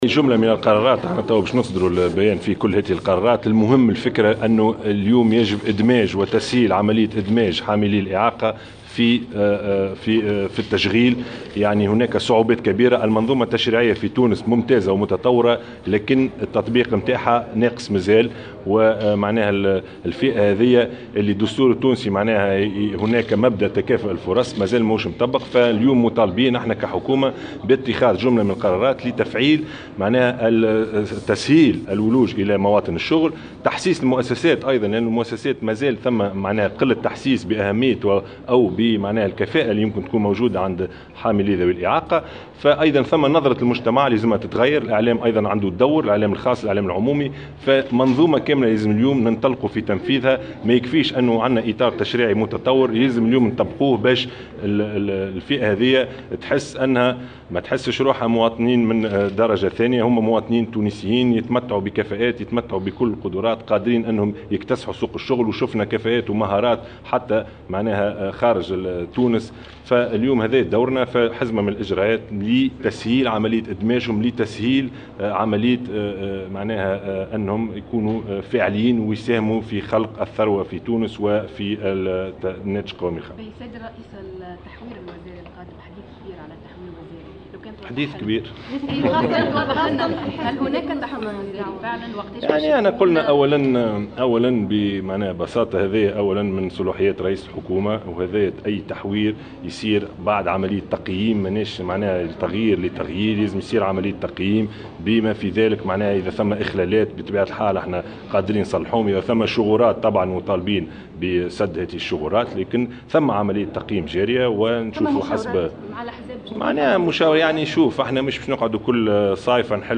قال رئيس الحكومة يوسف الشاهد اليوم الثلاثاء إن هناك عملية تقييم جارية بخصوص تشكيلة الحكومة الحالية، في ردّه على أسئلة الصحفيين حول تحوير وزاري محتمل.